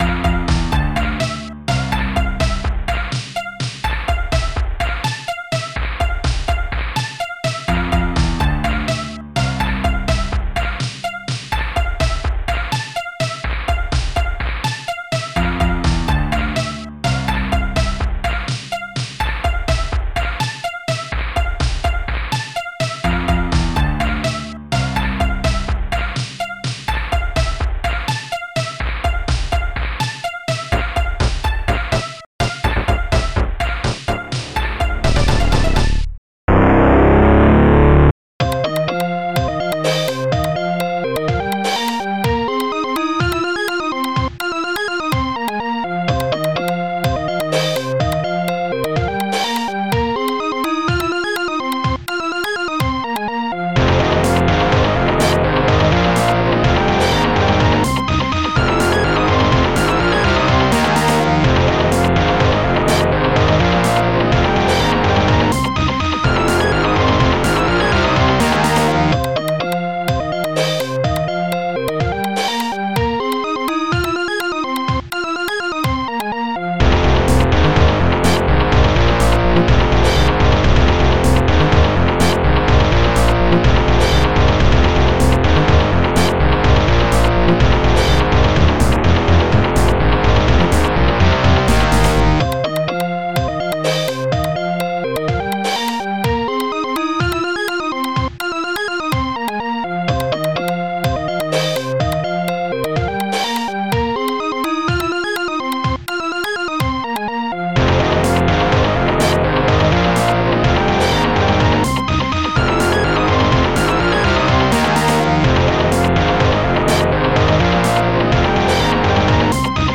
Protracker Module  |  1991-06-27  |  97KB  |  2 channels  |  44,100 sample rate  |  3 minutes, 44 seconds
Protracker and family
ST-02:A.Guitar1
ST-02:E.Guitar1
ST-02:GlockenSpiel
ST-02:PopSnare2
ST-01:JP.strings